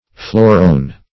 Search Result for " phlorone" : The Collaborative International Dictionary of English v.0.48: Phlorone \Phlo"rone\, n. [Phlorol + quinone.]